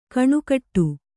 ♪ kaṇukaṭṭu